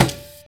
Index of /90_sSampleCDs/Optical Media International - Sonic Images Library/SI2_SI FX Vol 3/SI2_Gated FX 3